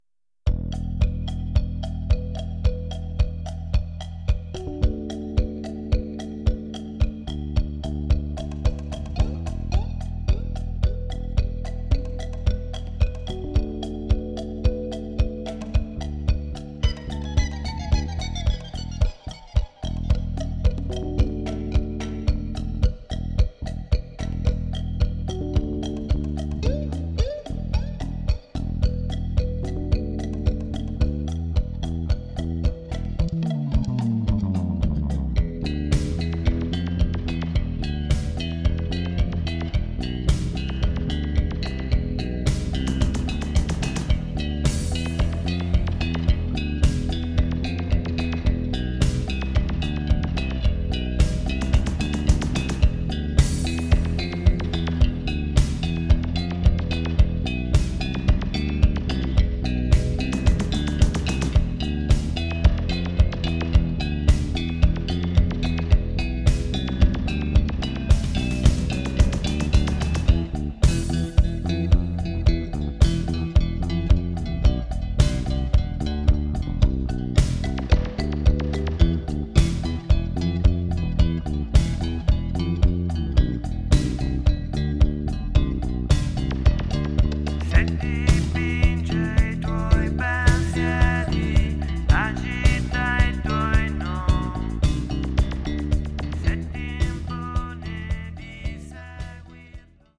Genere: Rock.